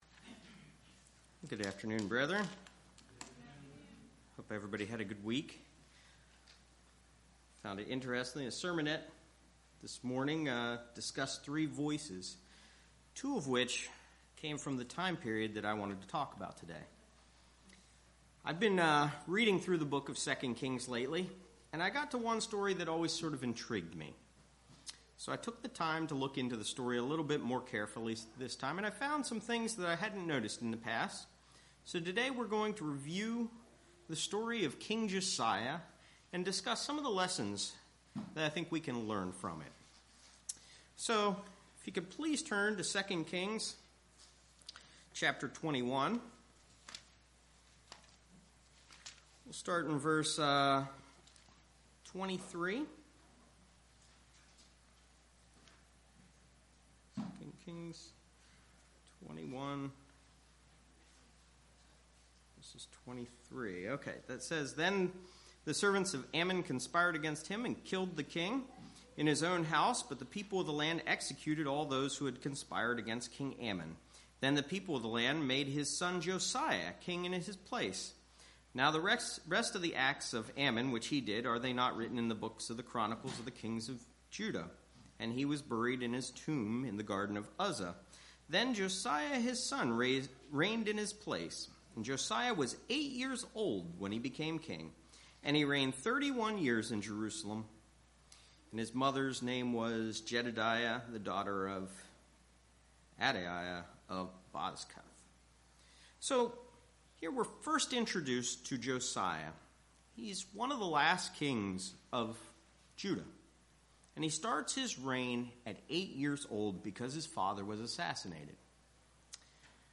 Given in Lehigh Valley, PA